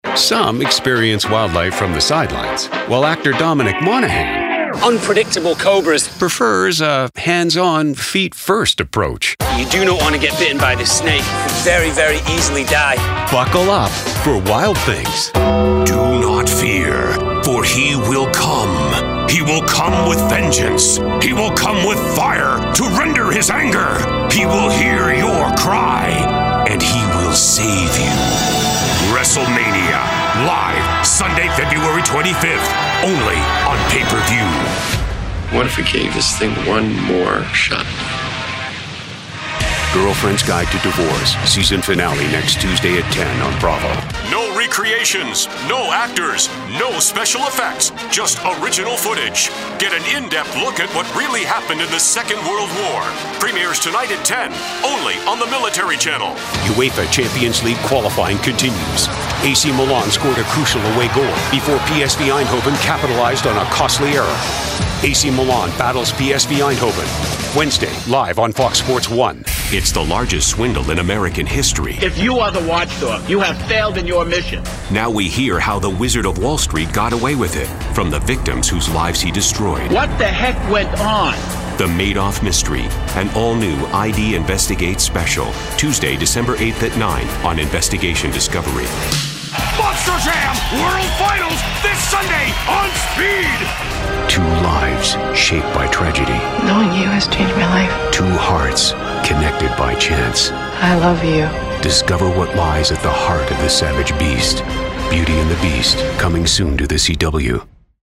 englisch (us)
Sprechprobe: Sonstiges (Muttersprache):
Bass baritone with a seasoned edge.